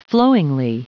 Prononciation du mot flowingly en anglais (fichier audio)
Prononciation du mot : flowingly